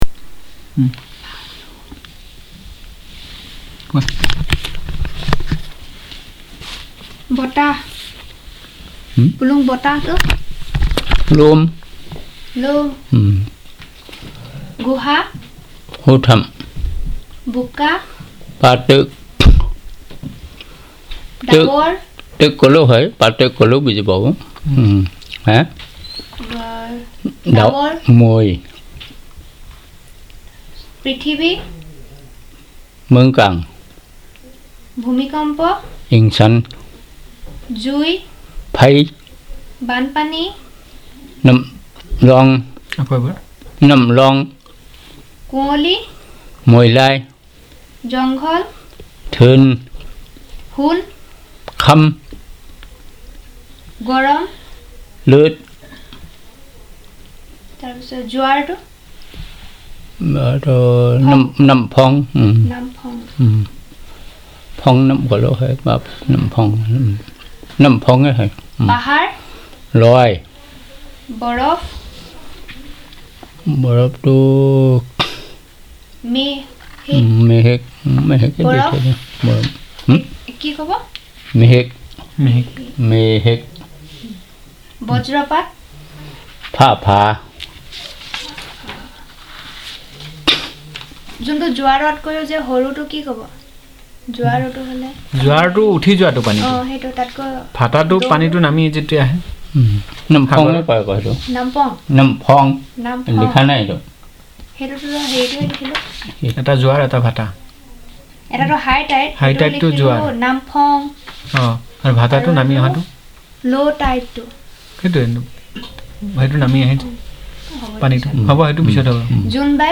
Elicitation of words on celestial bodies and related, earth and related, water and related, air and related, fire and related